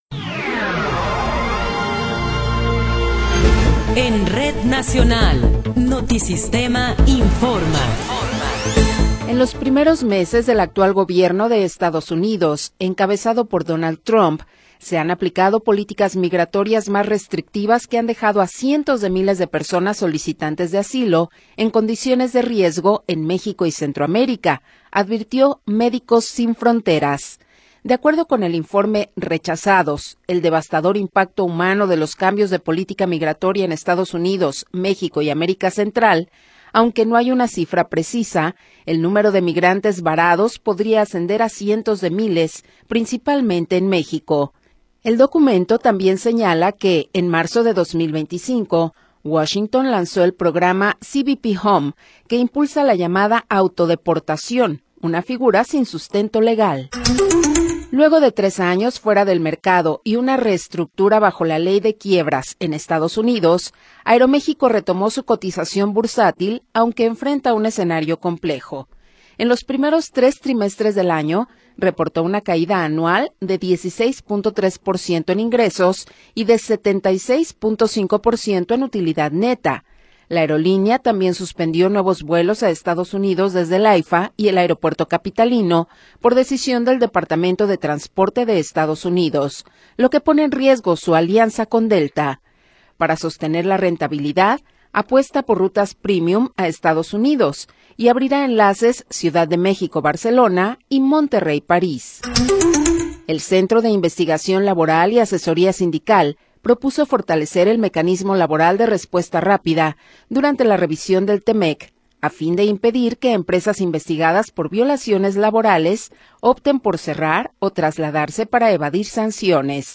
Noticiero 15 hrs. – 3 de Enero de 2026